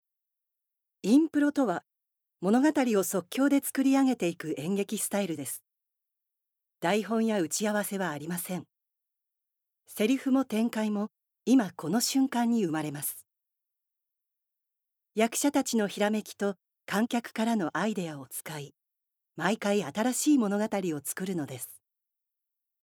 ボイスサンプル
ナレーション１